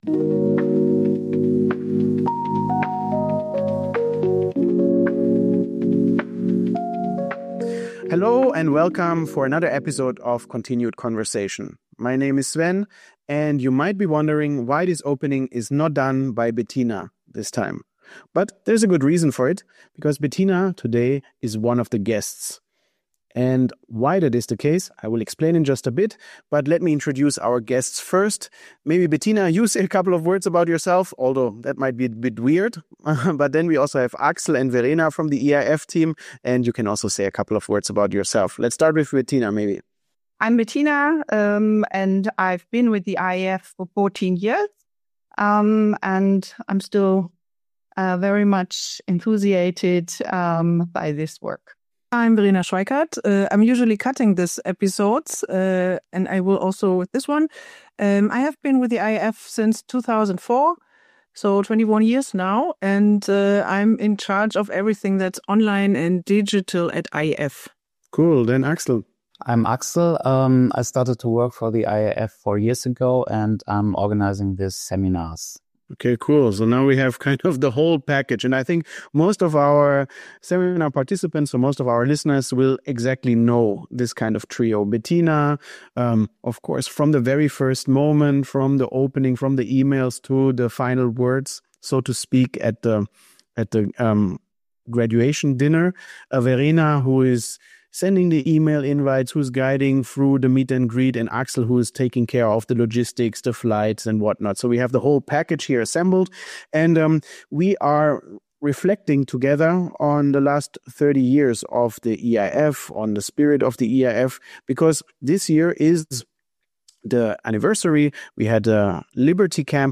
They discuss the spirit of Gummersbach, the craft behind great facilitation, the power of safe learning spaces and the countless human stories that defined the Academy. As the IAF closes its doors at the Magic Mountain, this conversation captures its legacy and the lessons that will continue to matter.